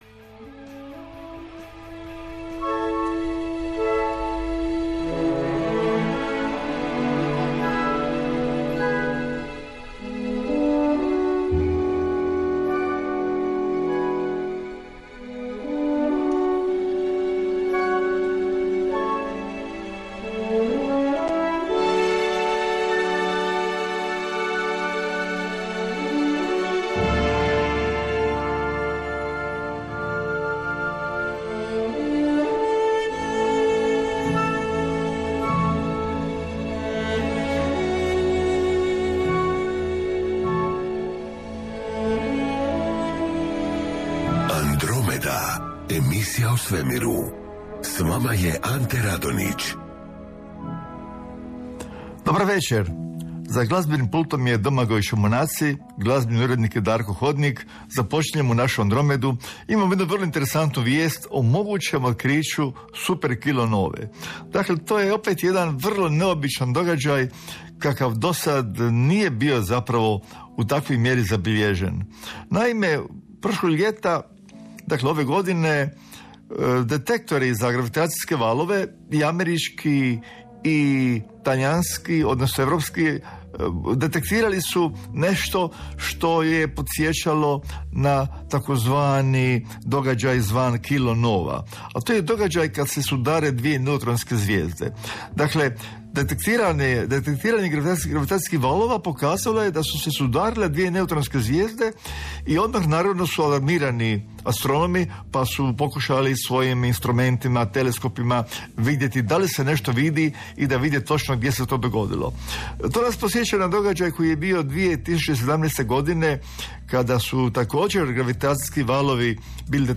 Andromeda Andromeda, 23.12.2025. Play episode Dec 23, 2025 2 hours Bookmarks Episode Description Andromeda je emisija koja više od dvadeset godina, utorkom, u našem radijskom prostoru educira javnost i podiže svijest o Svemiru i našem mjestu u njemu. Uz stručnog suradnika odgovara se na pitanja slušatelja, komentira vijesti iz astronomije, astrofizike i astronautike.